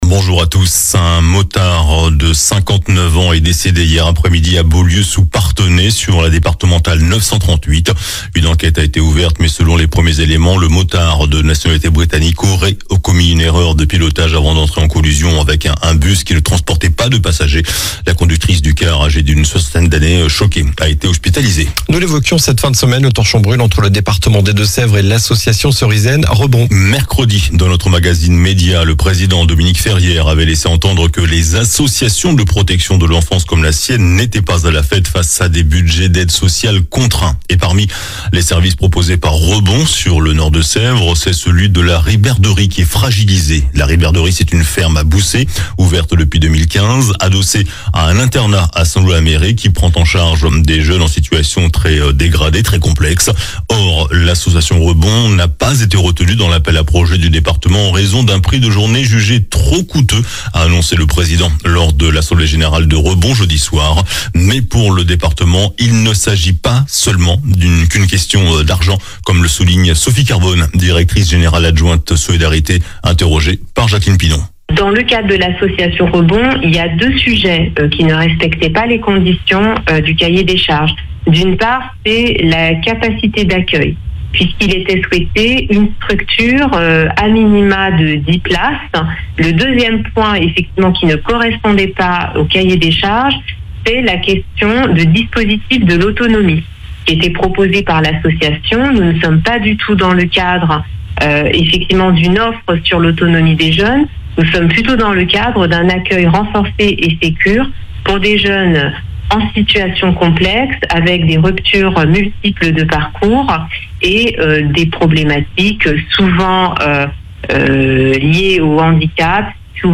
JOURNAL DU SAMEDI 25 MAI